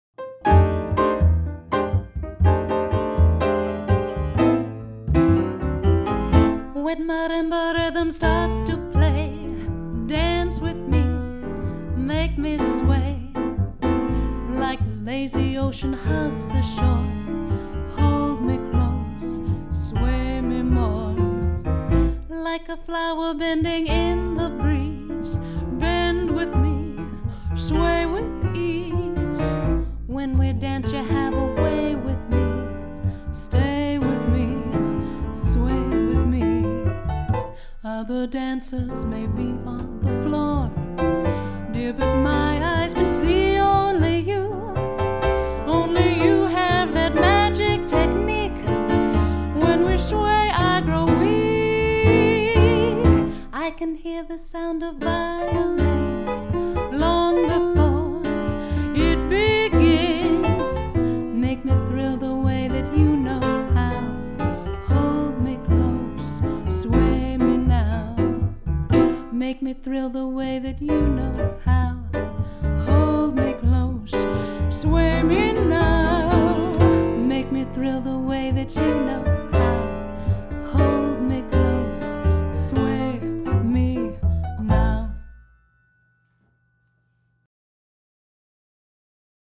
piano
bass.